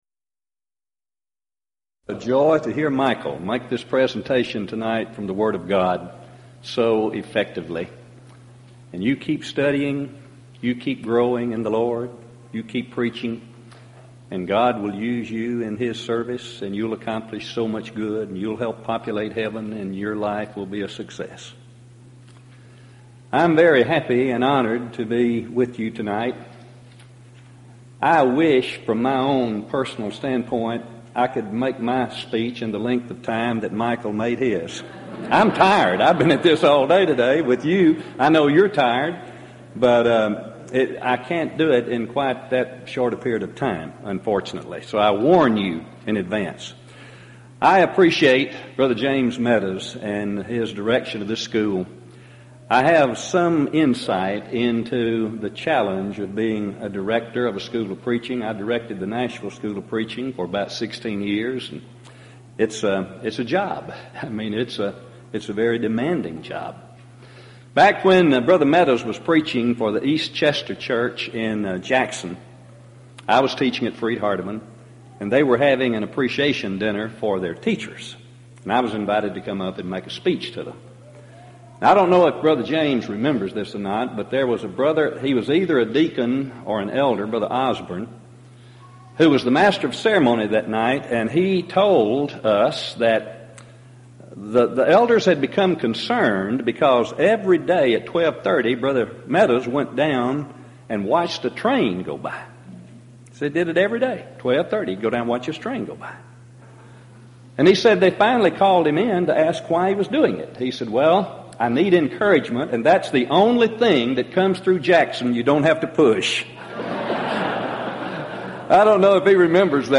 Event: 1998 East Tennessee School of Preaching Lectures Theme/Title: Studies in the Book of Leviticus
If you would like to order audio or video copies of this lecture, please contact our office and reference asset: 1998EastTenn13